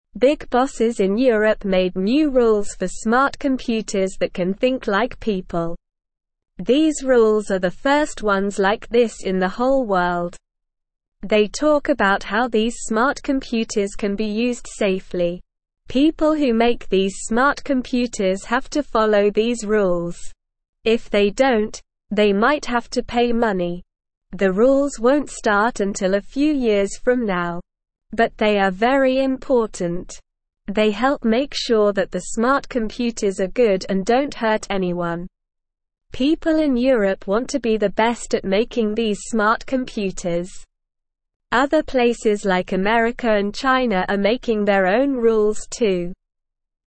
Slow
English-Newsroom-Beginner-SLOW-Reading-New-Rules-for-Smart-Computers-to-Keep-People-Safe.mp3